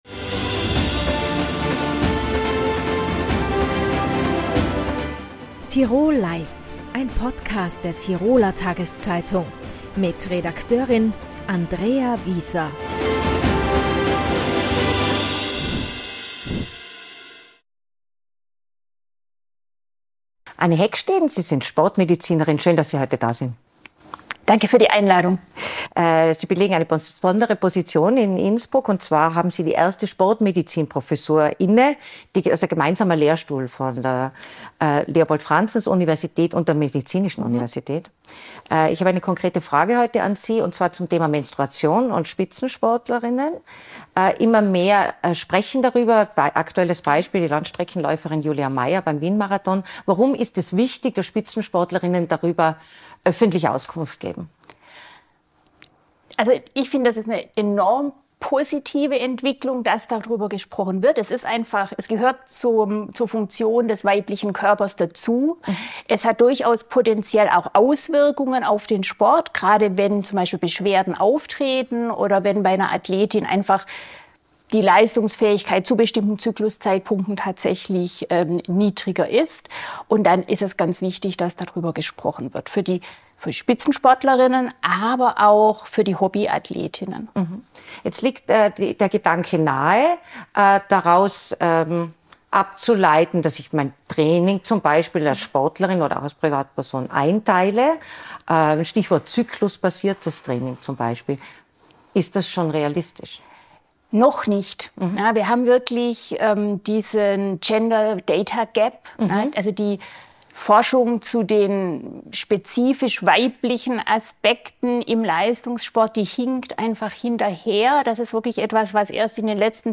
Im Gespräch bei „Tirol Live“ spricht sie über das immer noch